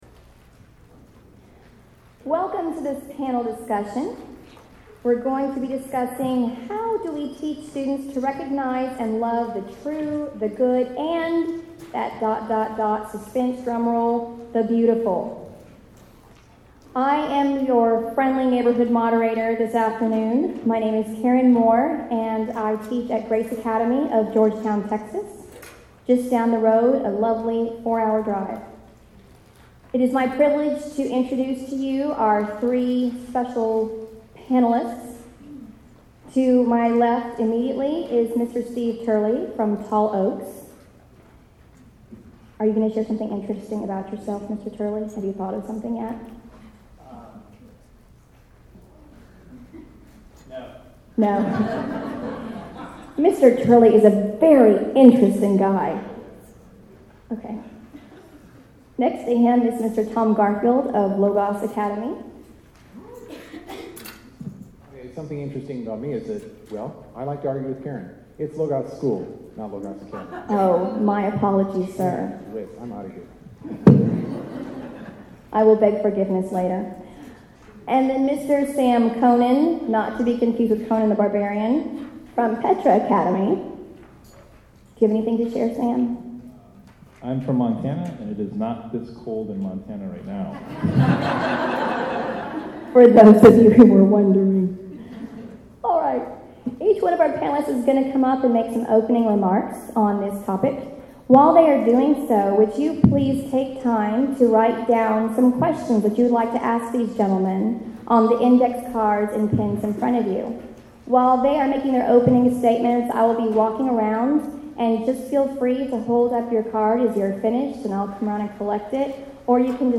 2012 Workshop Talk | 1:18:07 | All Grade Levels, Virtue, Character, Discipline
Each panelist will make opening remarks. Following these remarks, the panel will answer questions from the audience Speaker Additional Materials The Association of Classical & Christian Schools presents Repairing the Ruins, the ACCS annual conference, copyright ACCS.
How Do We Teach Students to Recognize and Love the True Good and Beautiful Panel Discussion.mp3